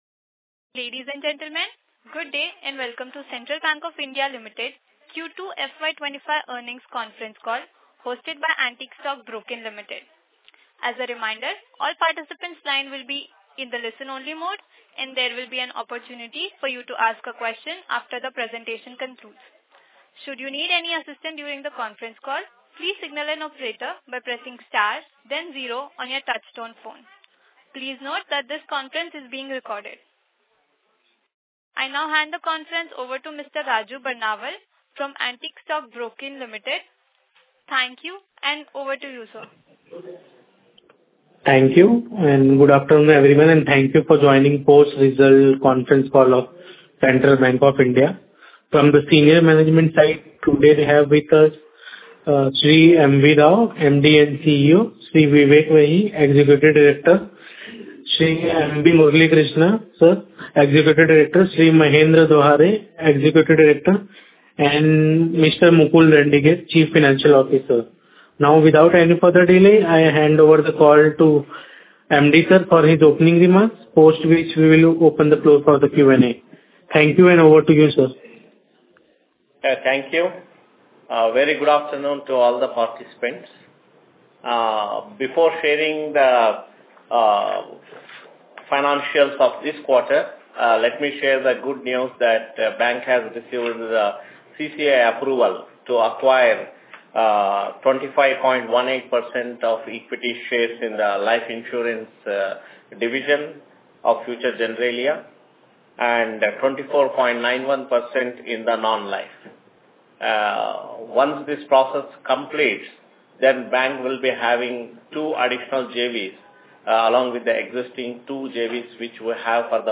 Conference Call with Analysts | Central Bank of India